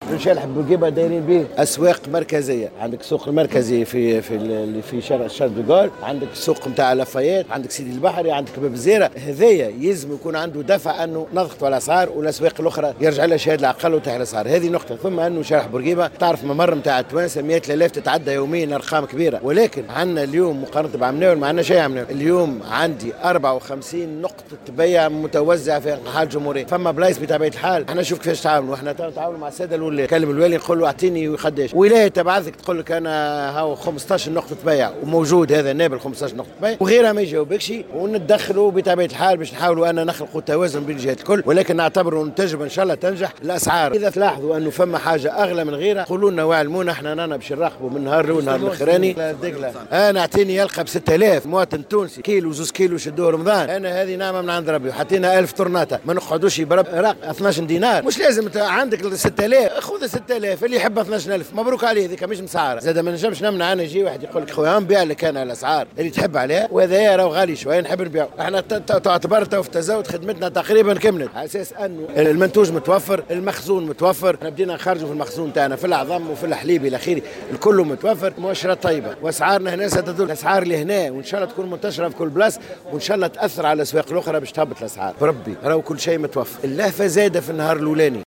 ودعا الطيب في تصريح لمراسل الجوهرة اف ام خلال افتتاحه اليوم فضاء من المنتج إلى المستهلك بشارع الحبيب بورقيبة، كافة المستهلكين إلى التخلي عن اللهفة.